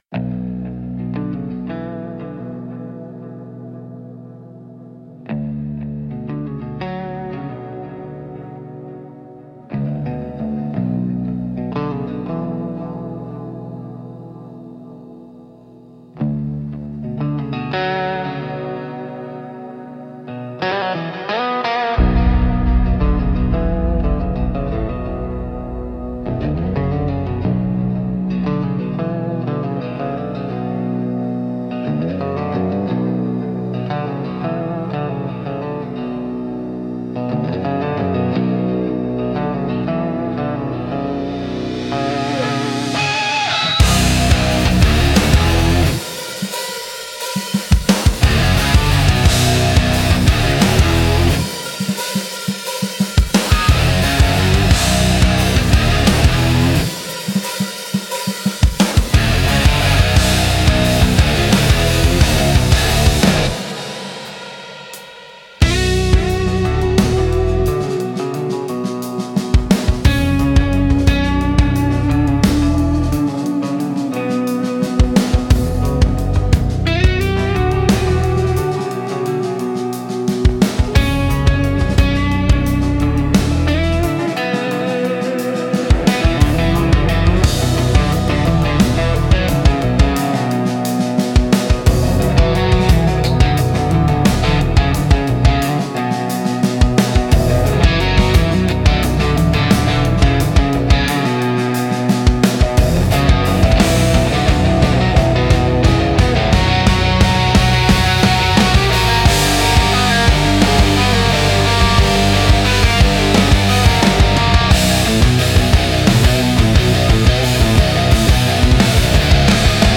Instrumental - Slow Burn Over Flatlands